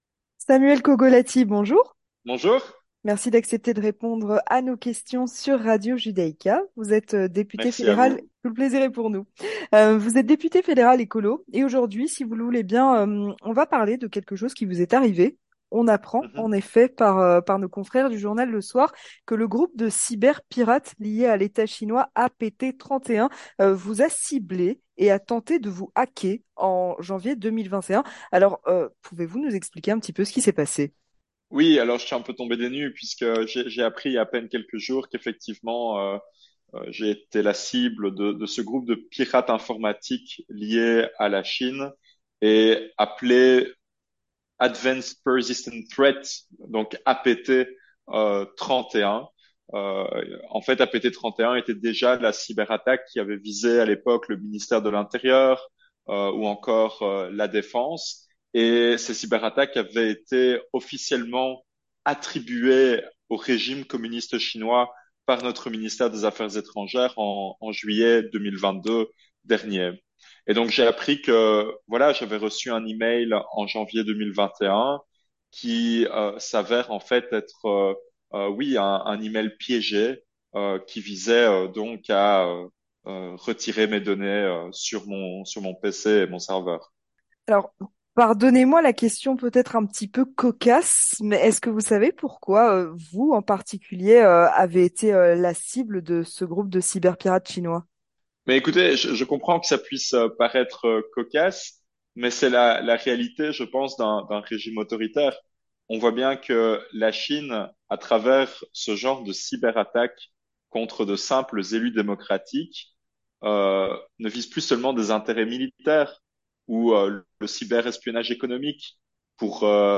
Avec Samuel Cogolati, député fédéral Ecolo